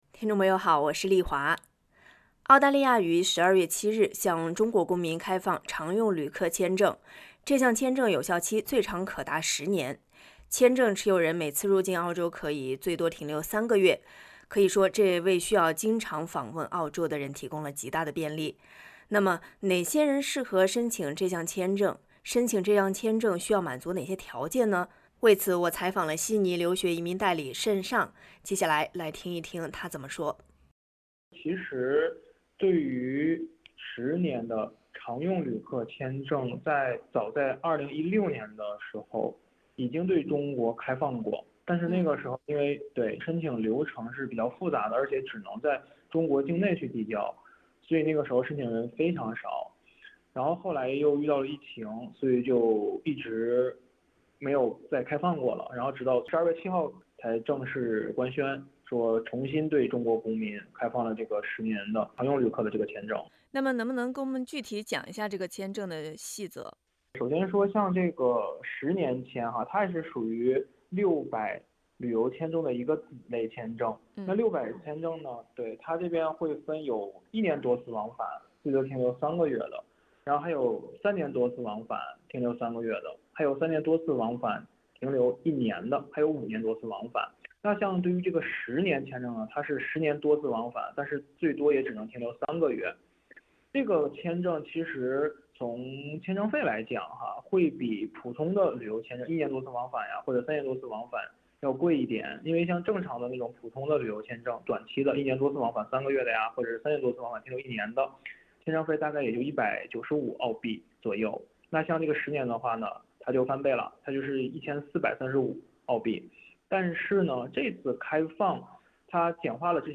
嘉宾观点，不代表本台立场。